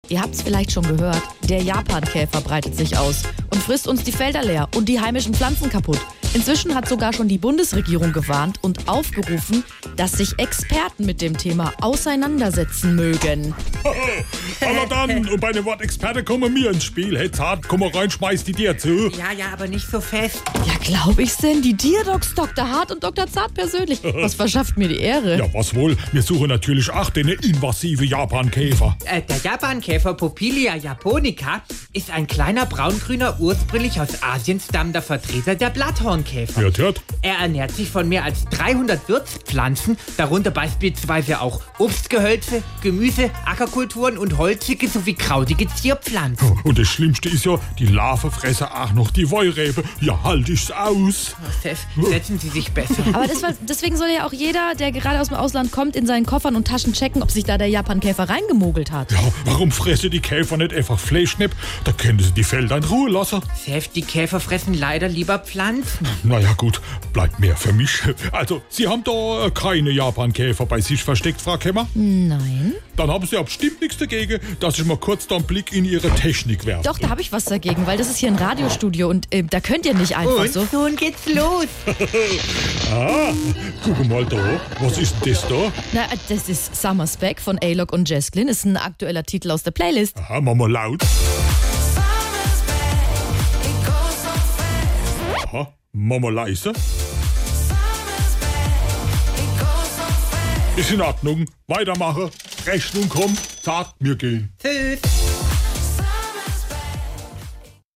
SWR3 Comedy Die Tierdocs suchen den Japankäfer